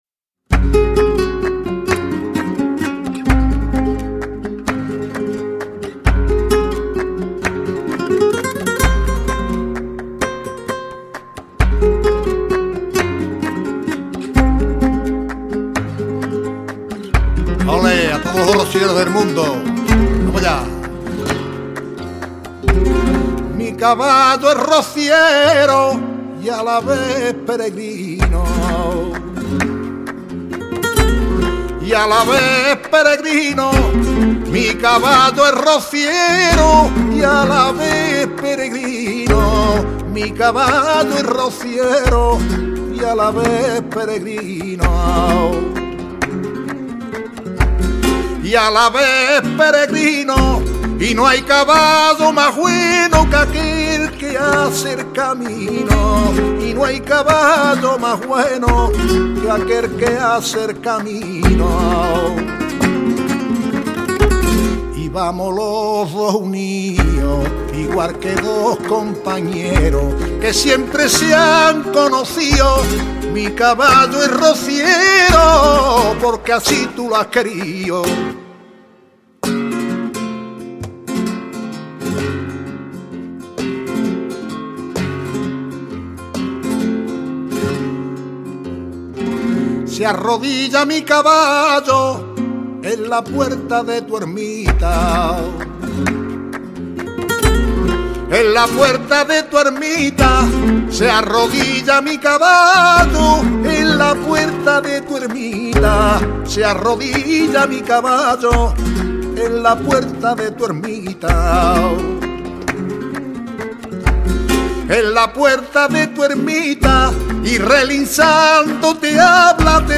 Sevillanas rocieras
Guitarras